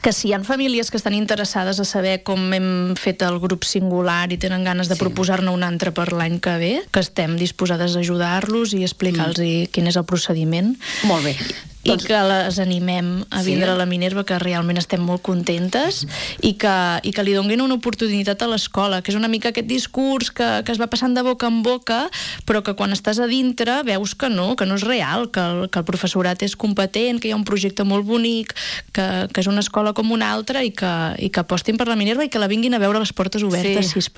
han passat aquest dilluns pel matinal de RCT per compartir la seva experiència després de dos mesos de curs.